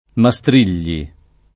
[ ma S tr & l’l’i ]